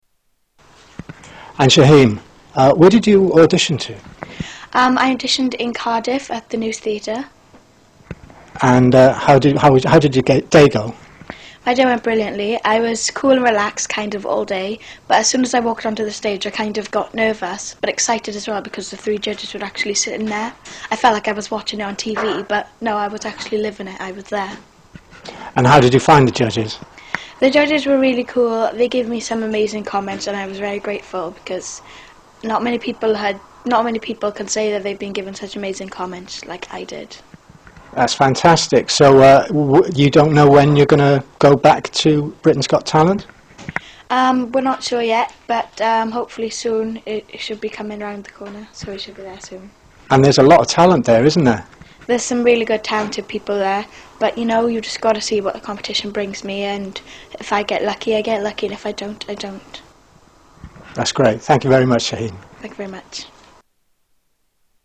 Short interview